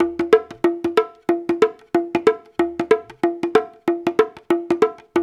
93 -UDU B04.wav